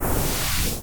Salut à tous ! Je m'amusais un peu sous Audacity aujourd'hui pour essayer de faire un petit bruitage de transition bien sympa.
Vous avez à présent deux pistes de bruit rose stéréo.
Pour qu'il passe de gauche à droite, nous allons mettre une piste à gauche (la première), une piste à droite (la deuxième) et utiliser des fondus d'ouverture/de fermeture.
Et pour le second problème, nous allons le régler par un simple effet de Phaser, avec les réglages de base.
Hé voilà ! Votre effet de transition est maintenant créé ;)
transition-2.wav